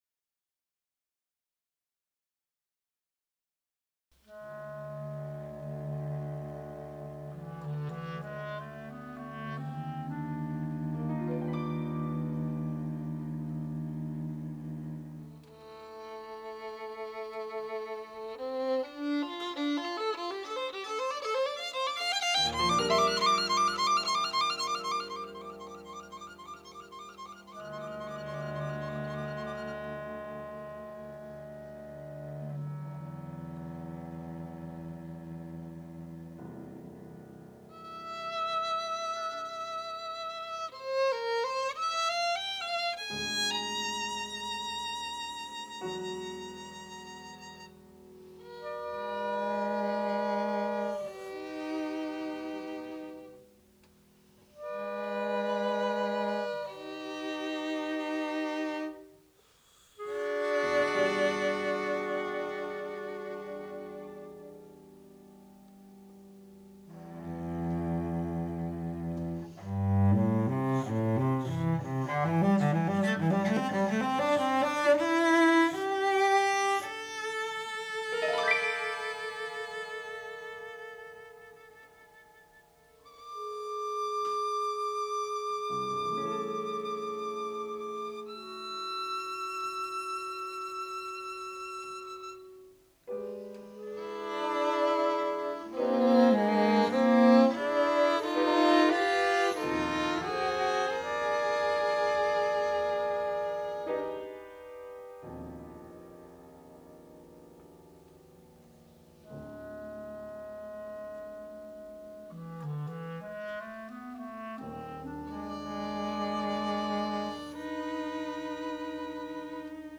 is joyous and light-hearted—full of excitement and zest.